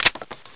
assets/ctr/nzportable/nzp/sounds/weapons/357/out.wav at e9d426c10d868c5ff3c693c1faa597ec4a549cf4